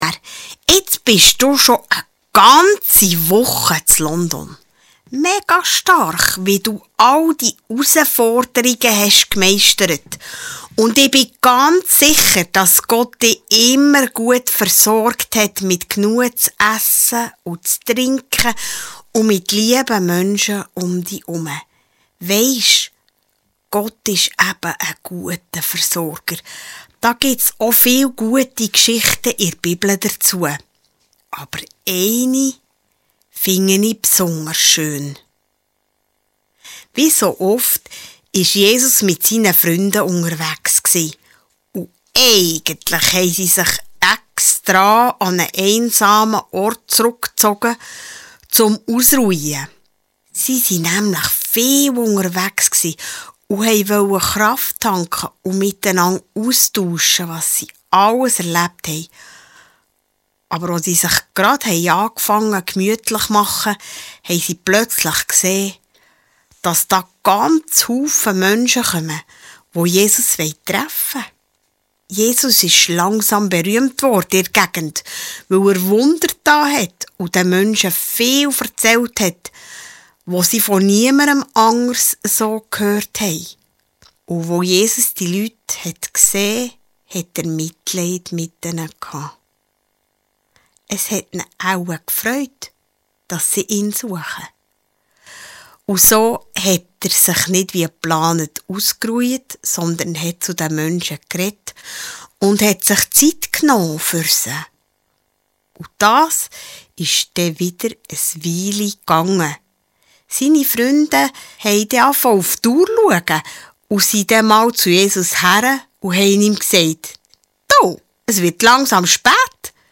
KiWo-Rückblick-Gottesdienst ~ Podcast FEG Langenthal